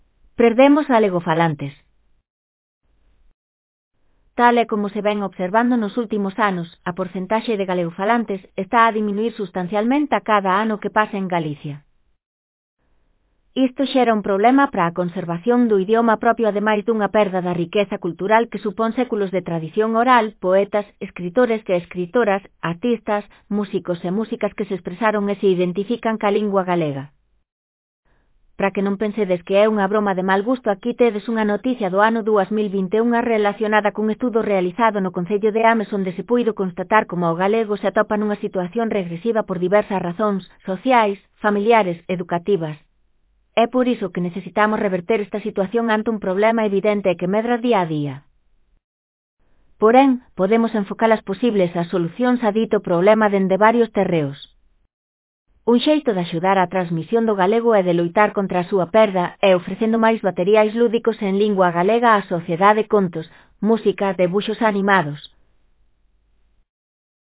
Para que non pensedes que é unha broma de mal gusto aquí tedes unha noticia do ano 2021 relacionada cun estudo realizado no concello de Ames onde se puido constatar coma o galego se atopa nunha situación regresiva por diversas razóns: sociais, familiares, educativas...